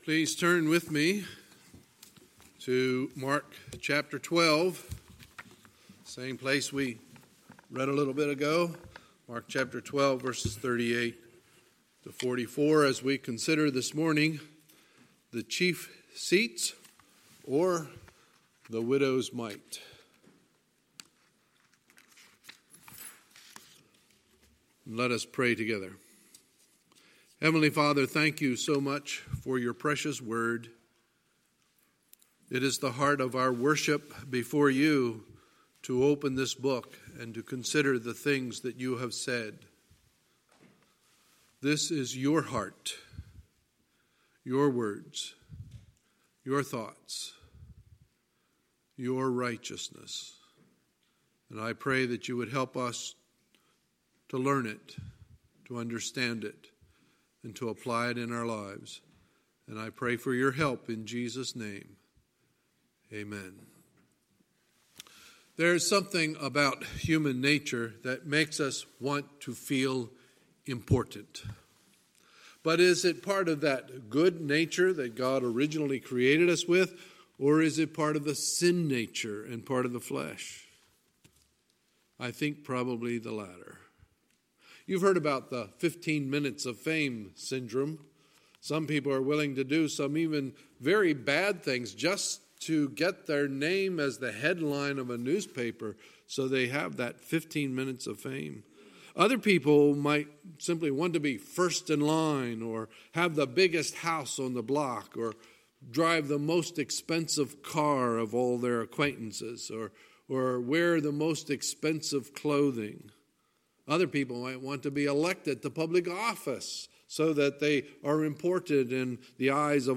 Sunday, October 13, 2019 – Sunday Morning Service